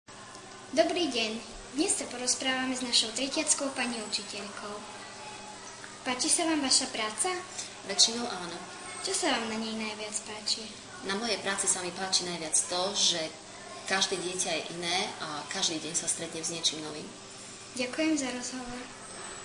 3 Rozhovor s tretiackou p. ucitelkou.MP3